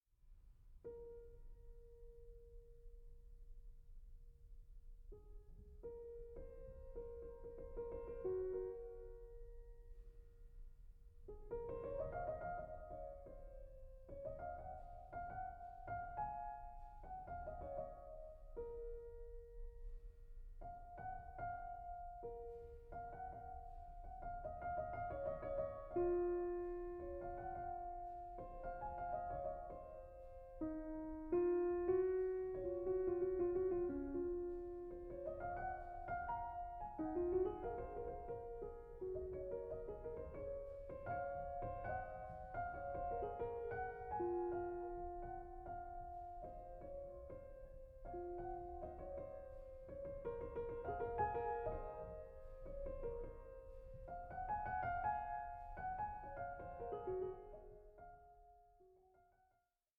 in B-Flat Minor: Prelude 2:23